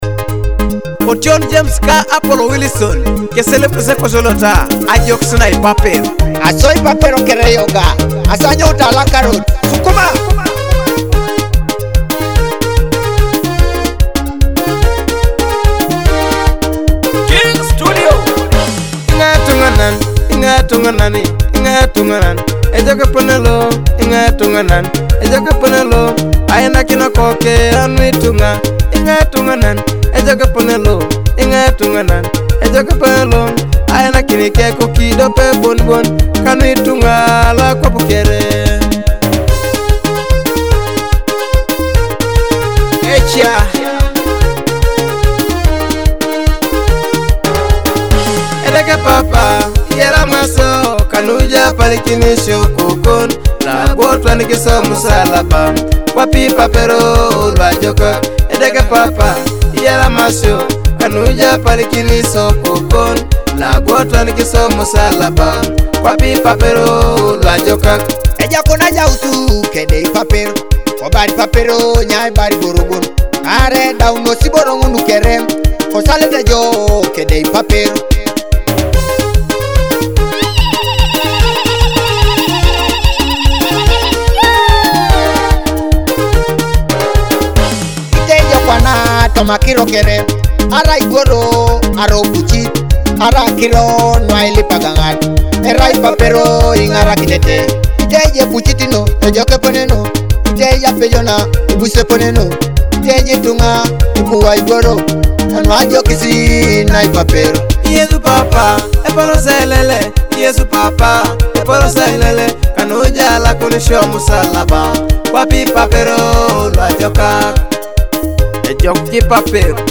soulful rhythms of Teso culture